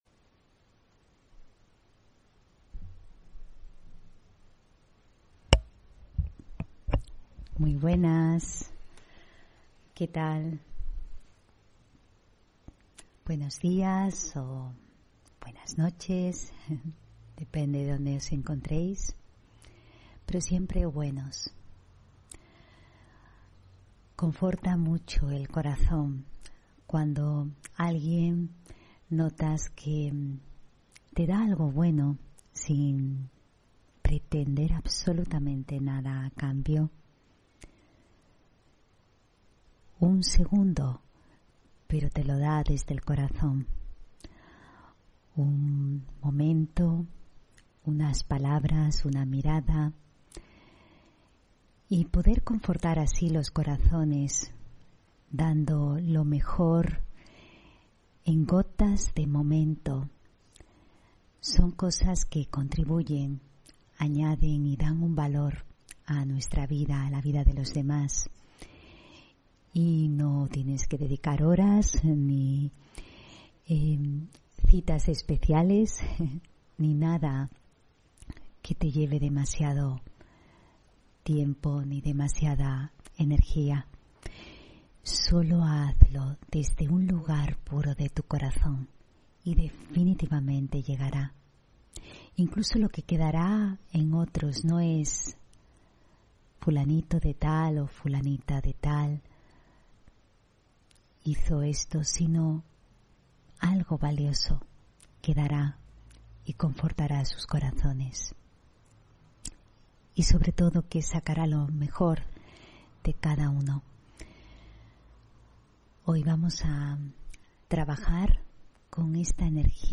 Meditación de la mañana: El amor que conforta el corazón